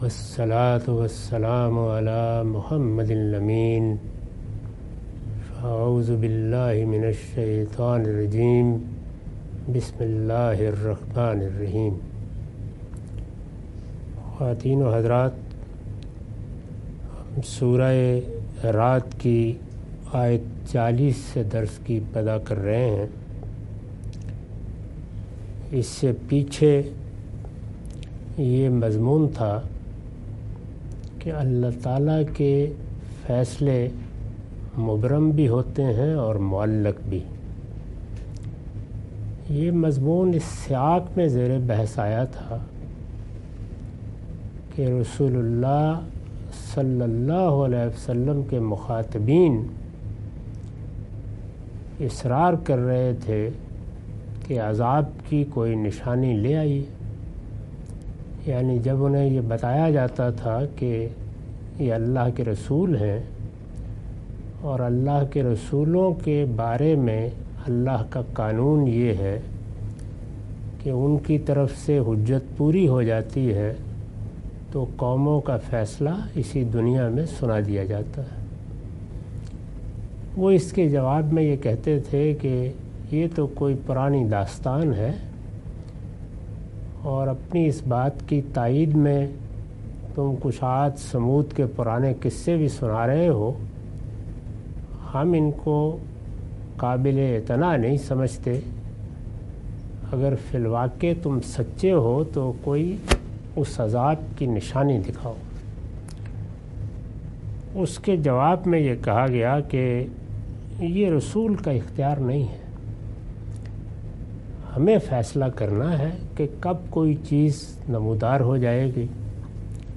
Surah Ar-Rad - A lecture of Tafseer-ul-Quran – Al-Bayan by Javed Ahmad Ghamidi. Commentary and explanation of verses 40-43.